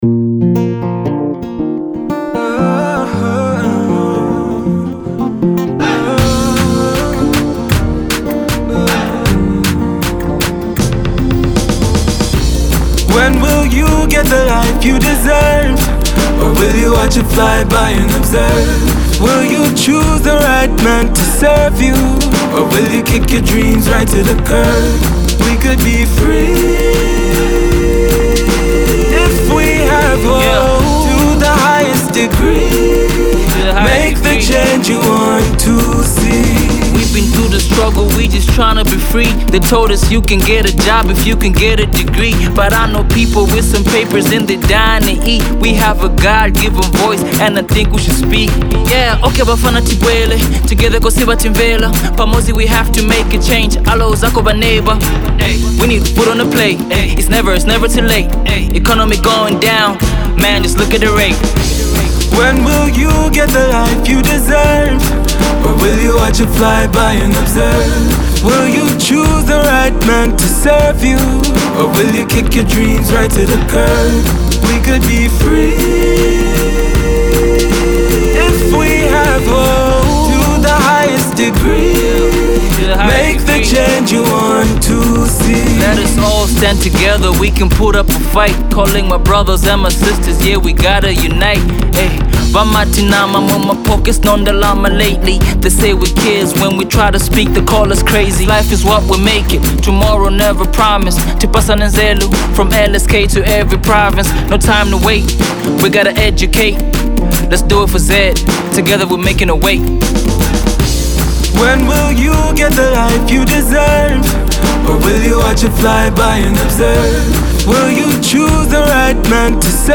heartfelt number